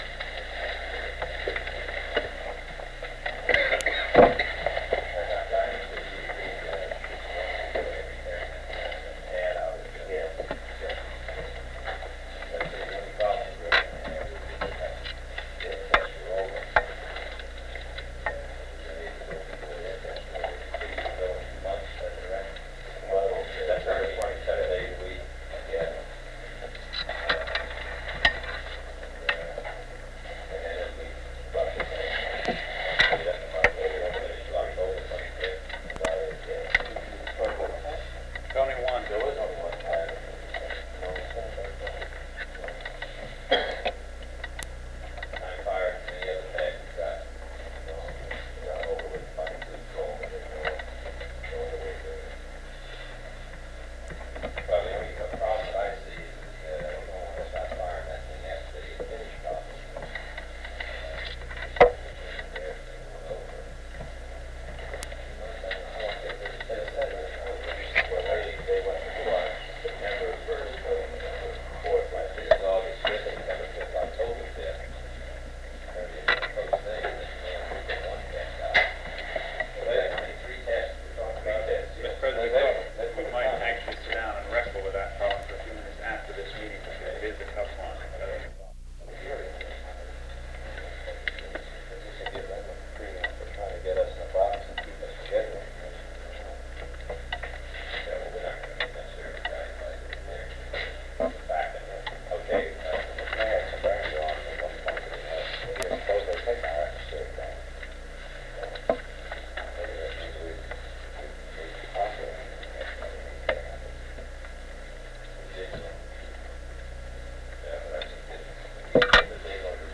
Secret White House Tapes | John F. Kennedy Presidency Meeting on Nuclear Test Ban Rewind 10 seconds Play/Pause Fast-forward 10 seconds 0:00 Download audio Previous Meetings: Tape 121/A57.